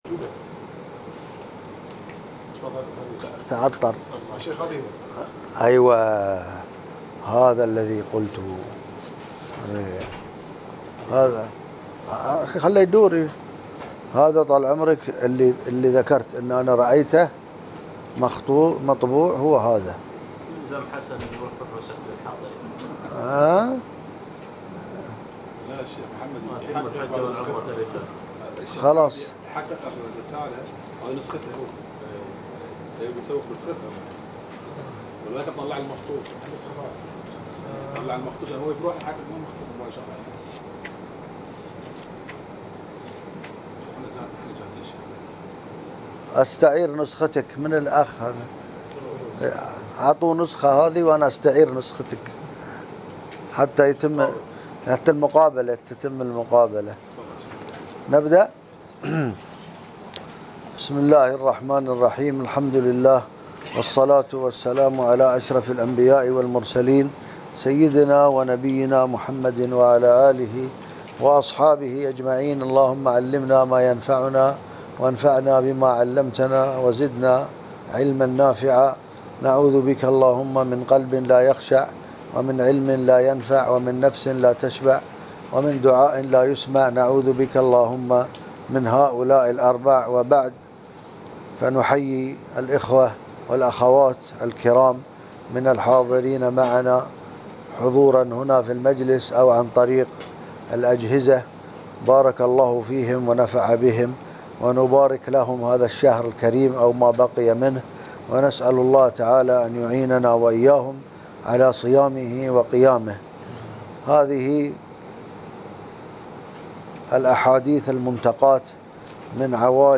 1- قراءة المائة المنتقاة من صحيح البخاري لابن تيمية 1440 - قسم المنوعات